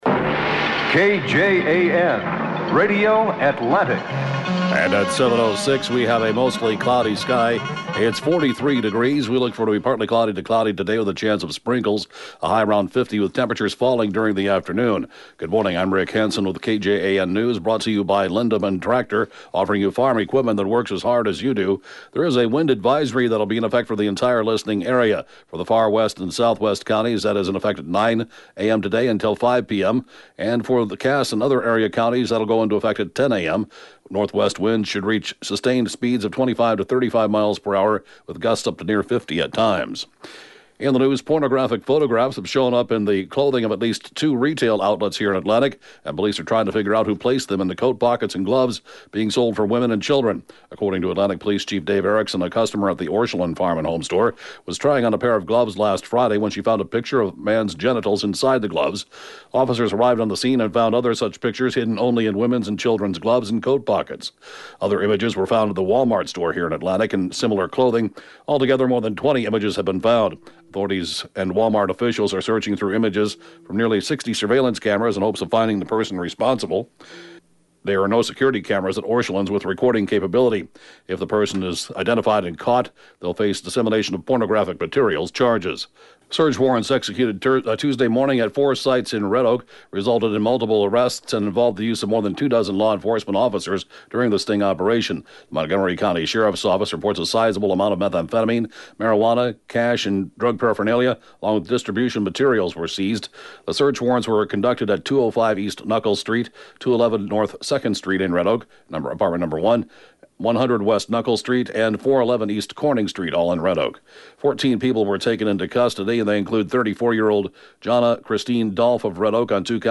The area’s latest and/or top news stories at 7:06-a.m.